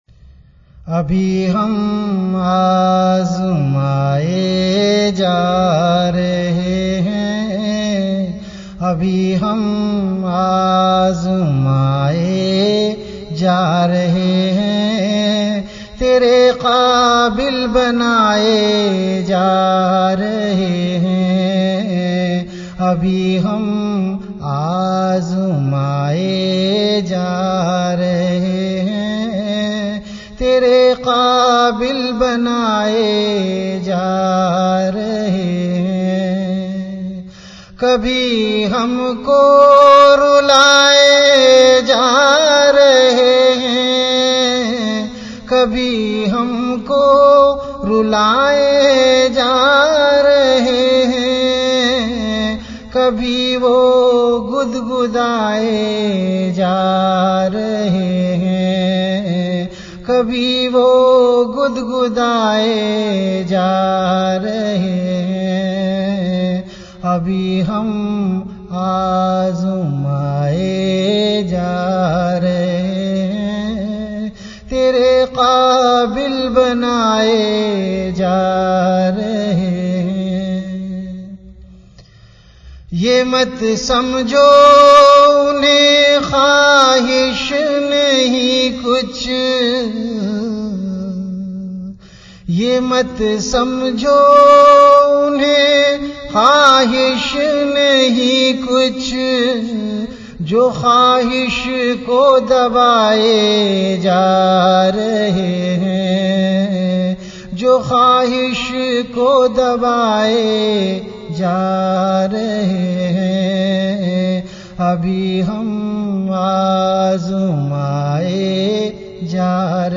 CategoryAshaar
VenueKhanqah Imdadia Ashrafia
Event / TimeAfter Isha Prayer